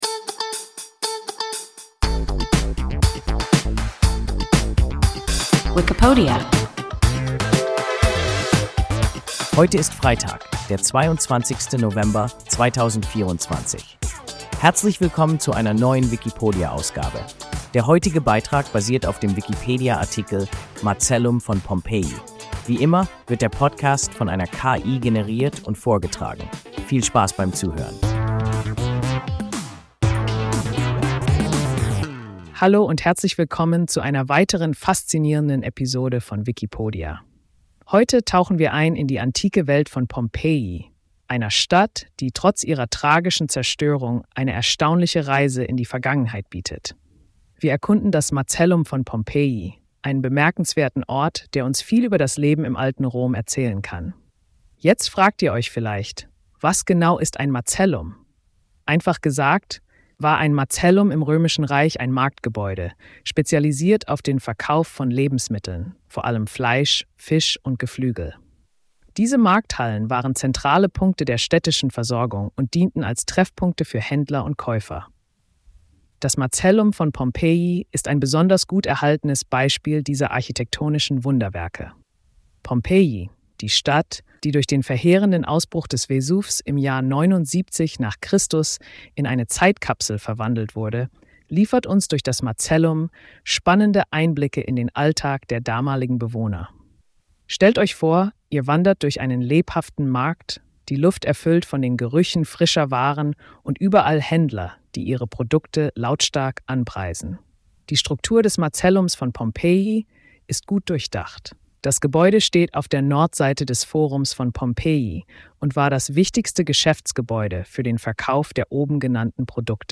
Macellum von Pompeji – WIKIPODIA – ein KI Podcast